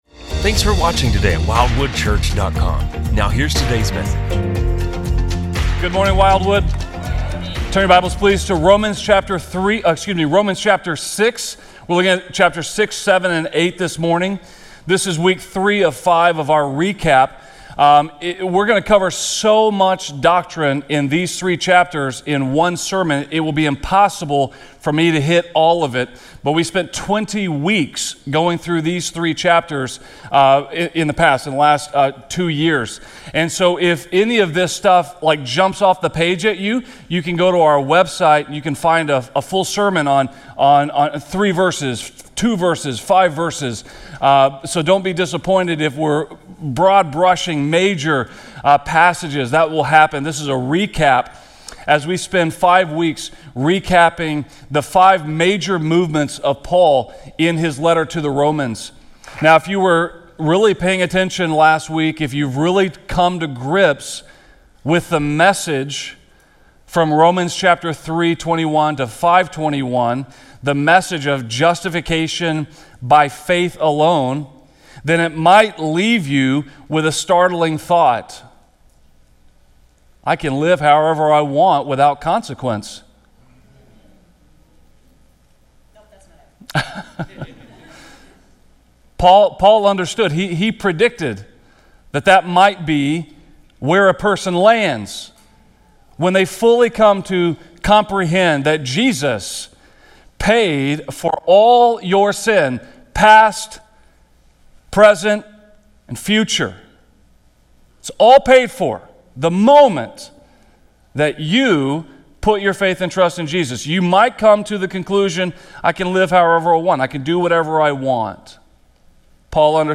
In this powerful sermon on Romans 6-8, we explore the believer’s transformation through faith in Jesus. Discover how true salvation not only secures our standing before God but also empowers us to break free from sin and walk in the Spirit.